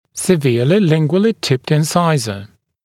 [sɪ’vɪəlɪ ‘lɪŋgwəlɪ tɪpt ɪn’saɪzə][си’виэли ‘лингуэли типт ин’сайзэ]сильно наклоненный в язычную сторону резец